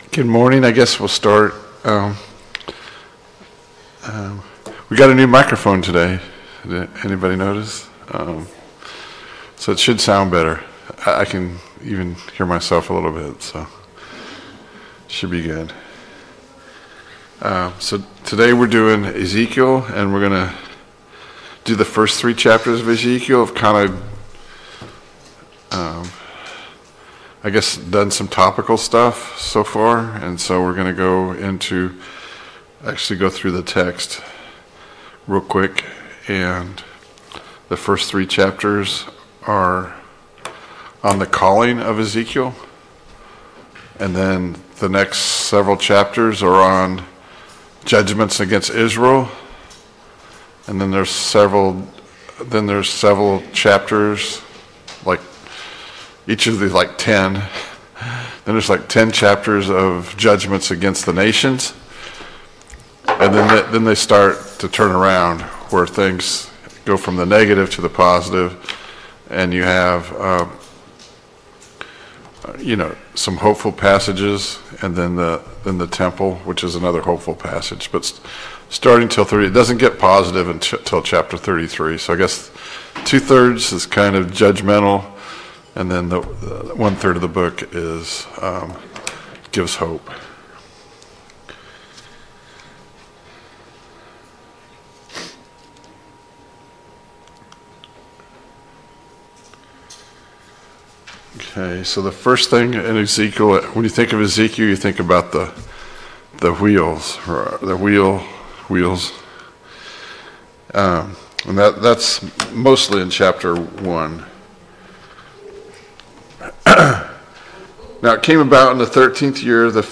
Sunday Morning Bible Class « Study of Paul’s Minor Epistles